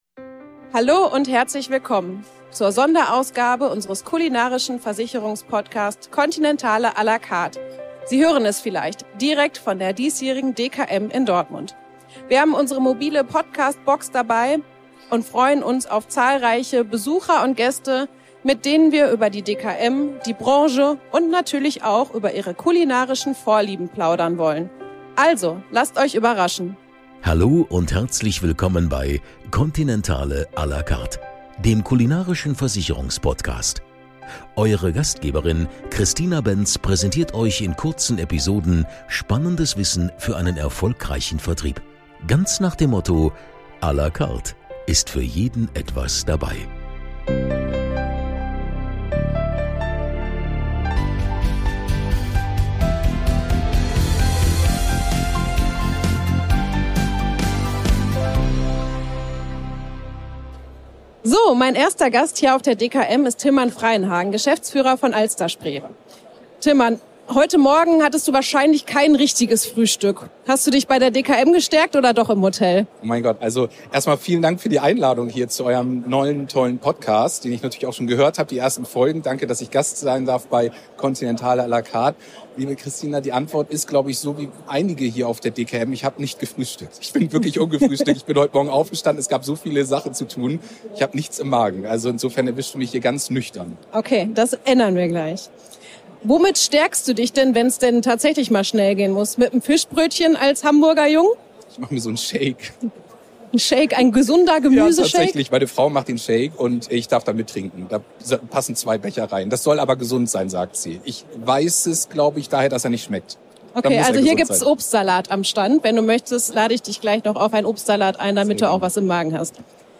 Von Alsterspree, über Fonds Finanz bis hin zu Swiss Life, wir hatten sie alle zu Gast und haben ihr geballtes Expertenwissen in einer Folge vereint. Die Vielfalt der Gäste verspricht eine facettenreiche Diskussion über aktuelle Trends und Herausforderungen in der Versicherungswelt. Von innovativen Produktlösungen bis hin zu Zukunftsvisionen für den Versicherungsmarkt – die Gespräche decken ein breites Spektrum ab und liefern wertvolle Einblicke.